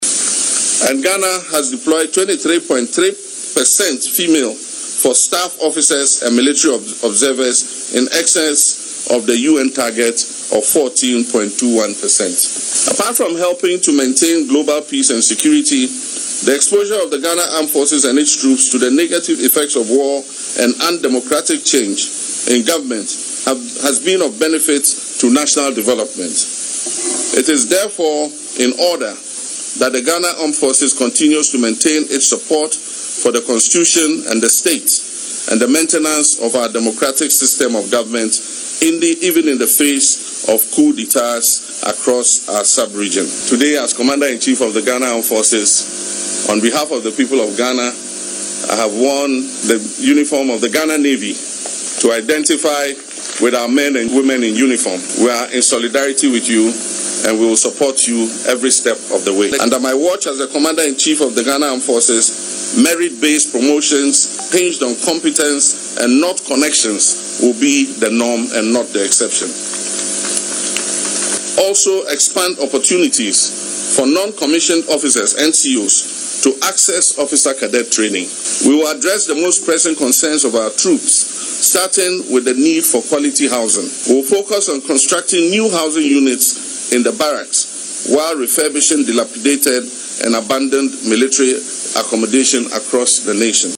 Speaking at a graduation ceremony at the Ghana Military Academy on Friday, April 11, the President said the Asantehene had assured him of his commitment to reconvene stakeholders and continue the peace dialogue upon his return from a brief visit abroad.